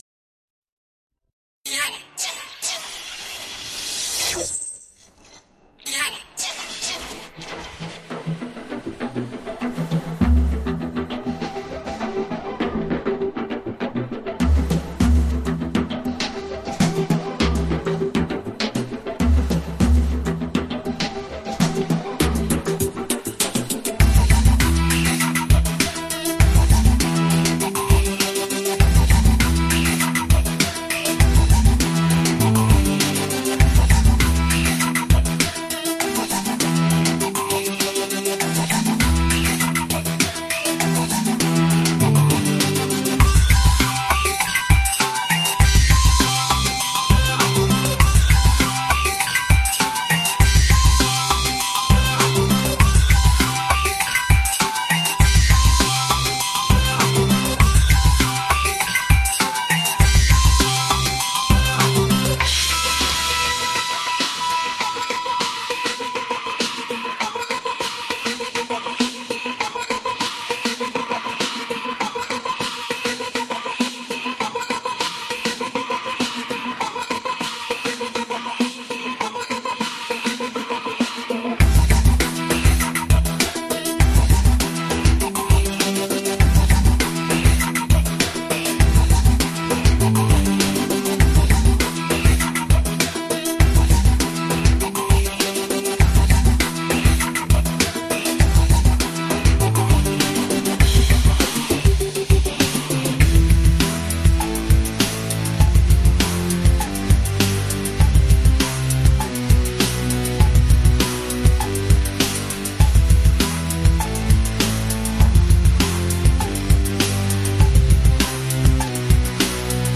【構成】 ・イントロは、軽快なビートとシンセサイザーのリフから始まり、力強いドラムビートが徐々に加わります。
最後には、ドラムビートとシンセサイザーのリフが残り、撮影の終わりを締めくくります。